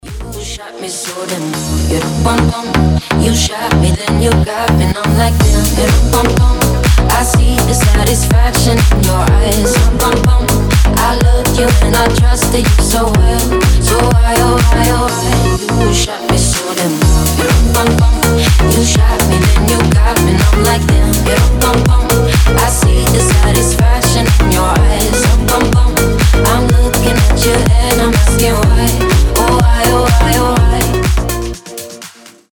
• Качество: 320, Stereo
ритмичные
заводные
женский голос
Club House
ремиксы